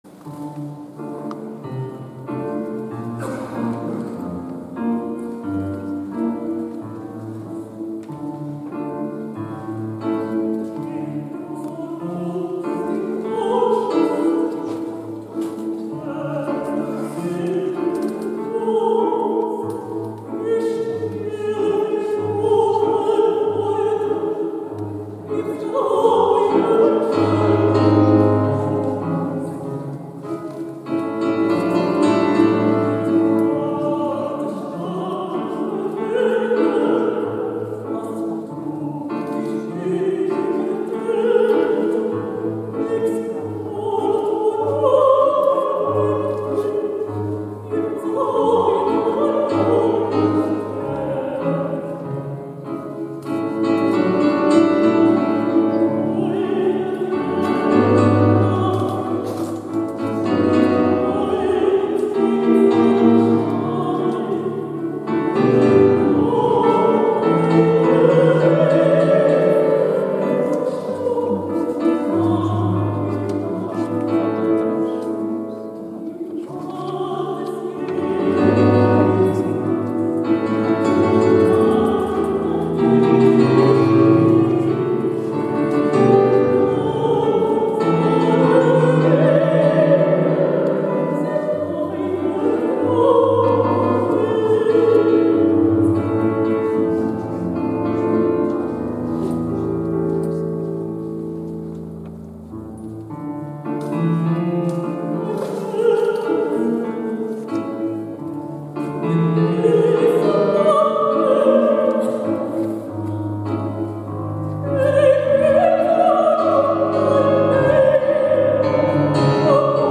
Actuació musical: Auf dem Flusse, F. Schubert.
a l’Aula Magna Modest Prats de l'edifici de Sant Domènec, al campus de Barri Vell de la Universitat de Girona